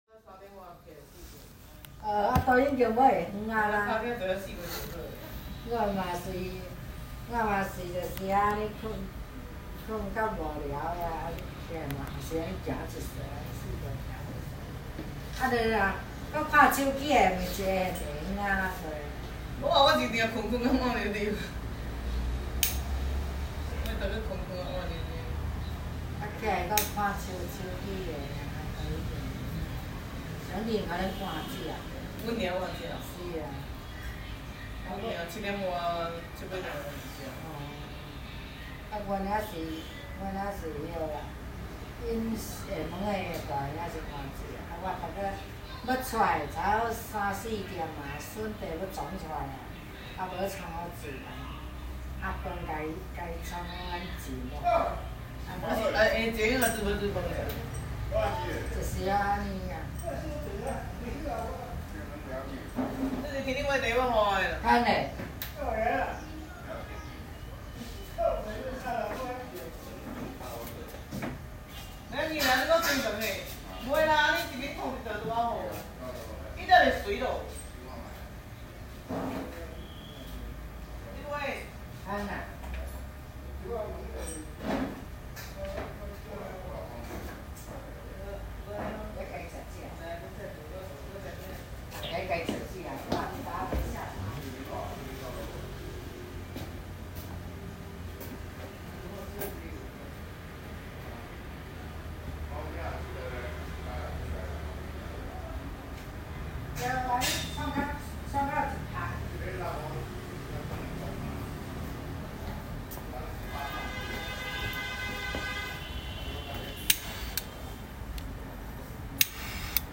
After relocating to Xiamen for work, I visited the Xiamen A-Ma Temple one afternoon. In this recording, administrators chat loudly in Minnanese on a quiet afternoon with few visitors. Amidst the chatter, faint sounds of lighters represent devotees offering incense to A-Ma.